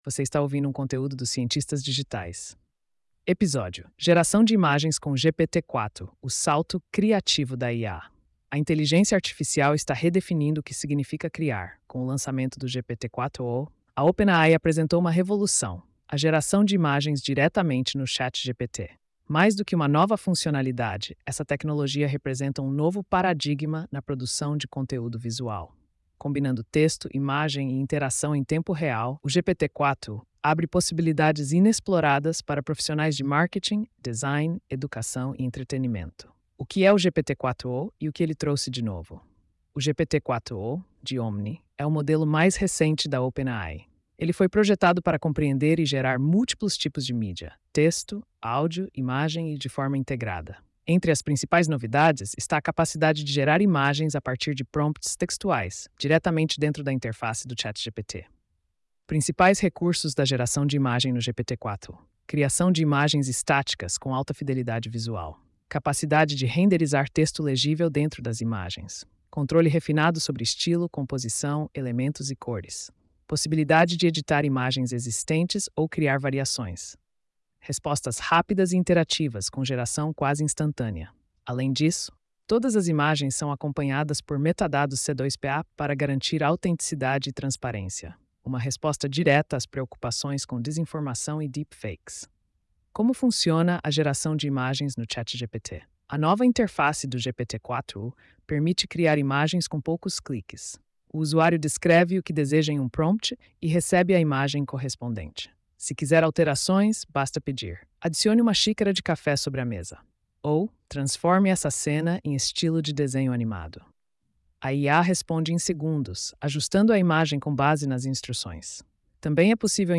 post-2972-tts.mp3